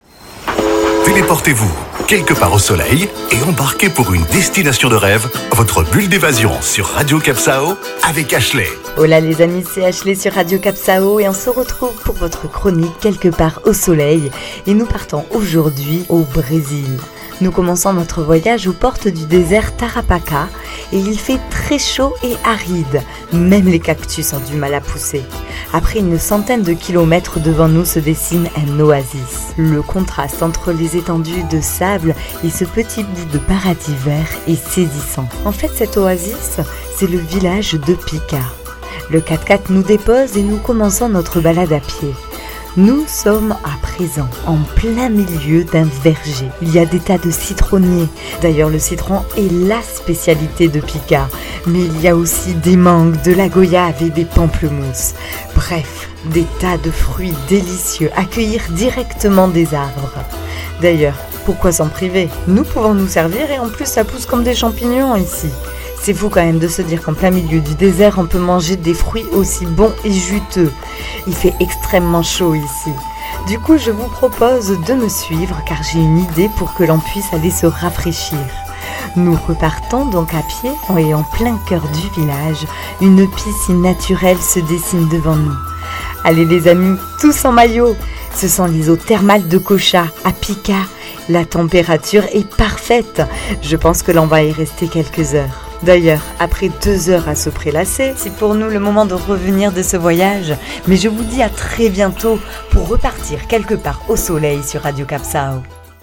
Carte postale sonore : peut-être est-ce un mirage, mais vous voilà au coeur du village de Pica, avec son verger rempli de fruits juteux et sa piscine naturelle pour se rafraîchir.